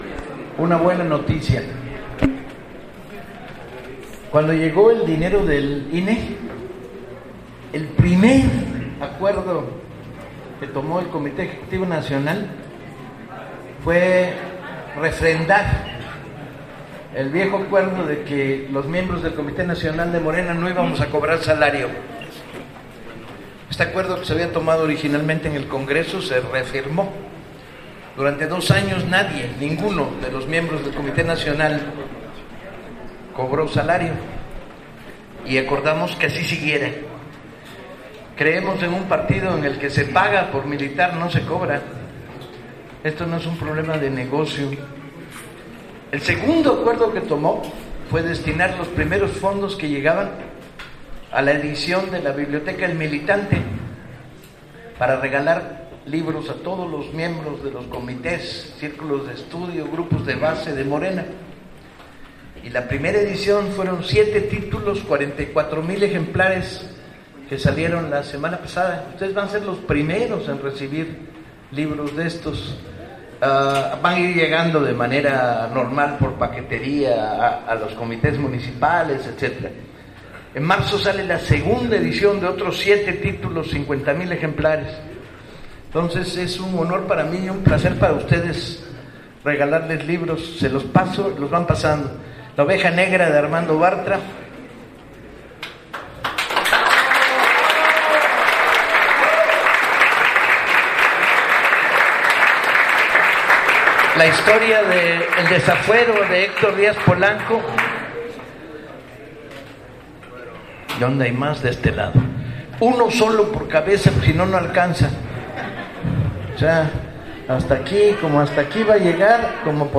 Conferencia de Paco Ignacio Taibo II
Este sábado 24 de enero de 2015 el escritor y activista de izquierda, Paco Ignacio Taibo II compartió una conferencia acerca del Movimiento de Regeneración Nacional (MORENA) hoy tambien partido político.
Autor: Archivosonoro Fecha: 24 de enero de 2015 Hora: 10:30 a.m. Lugar: Salón Imperial de Tuxtla Gutierrez, Chiapas.